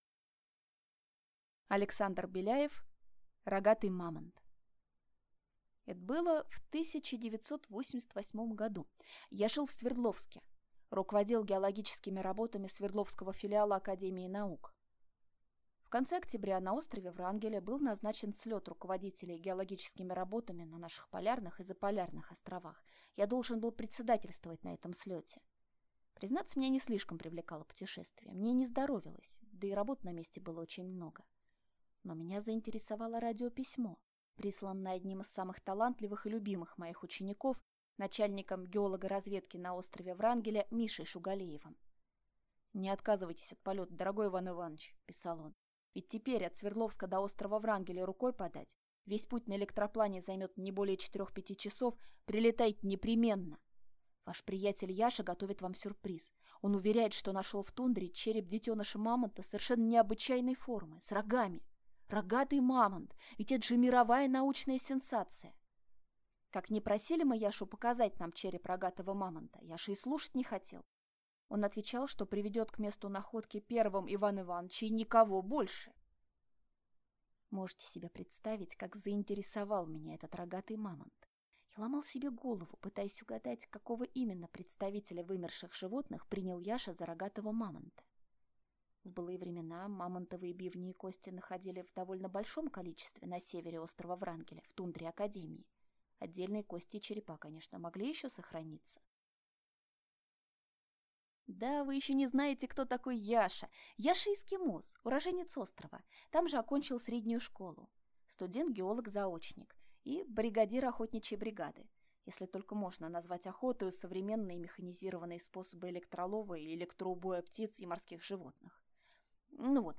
Аудиокнига Рогатый мамонт | Библиотека аудиокниг
Прослушать и бесплатно скачать фрагмент аудиокниги